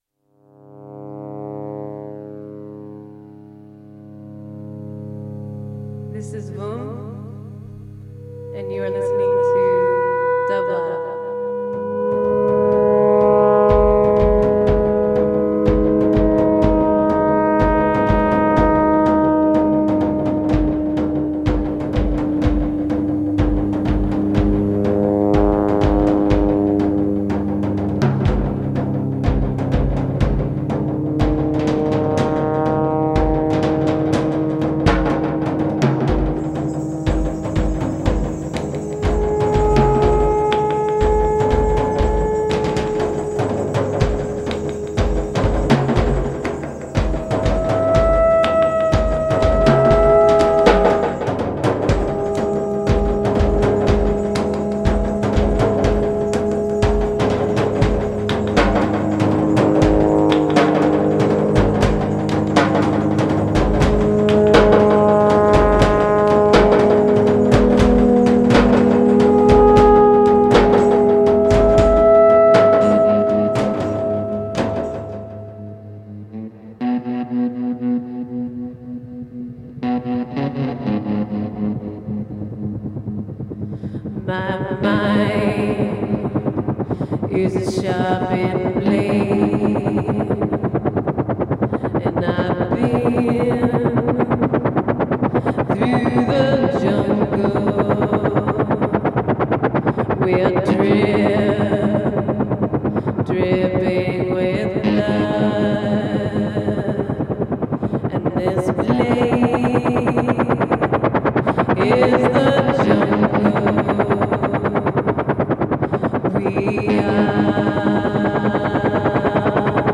gorgeous mystery music
live from the same Topanga Canyon house
Avant-Garde Electronic Rock Synth